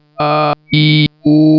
Humano moderno
human_aiu.wav